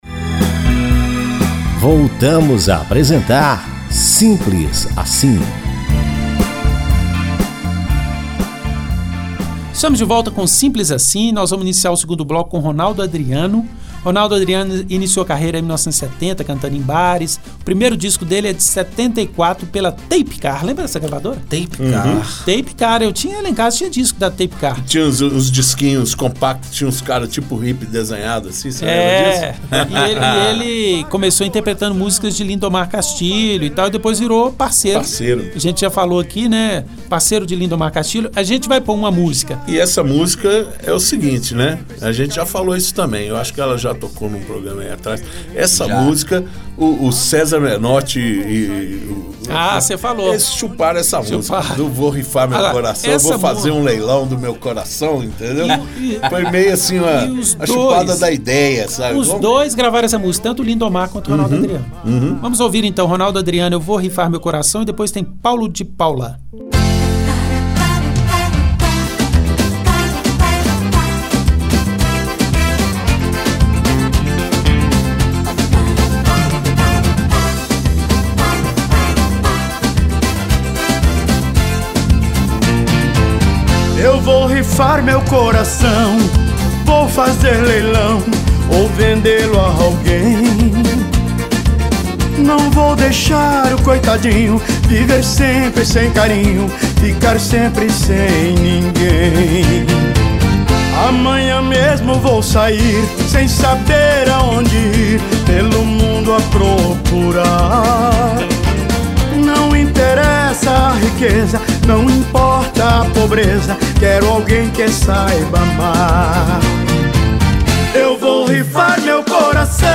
Música Brasileira Música romântica